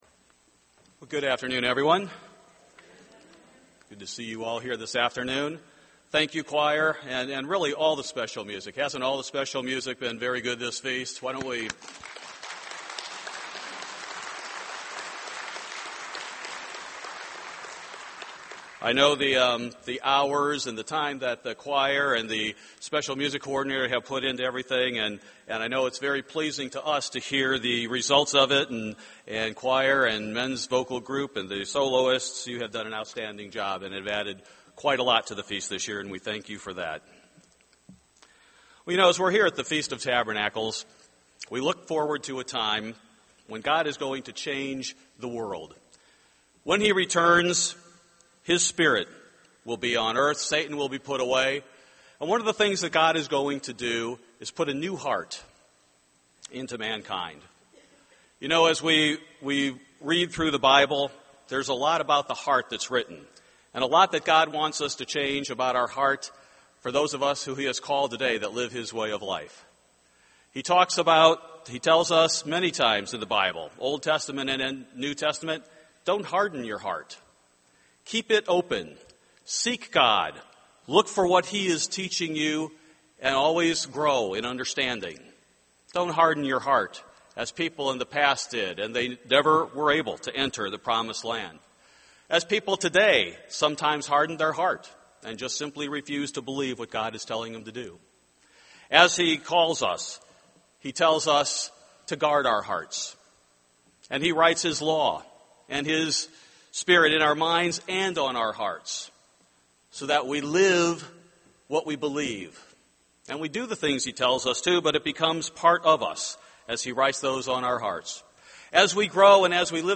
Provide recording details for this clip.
This sermon was given at the Jekyll Island, Georgia 2012 Feast site.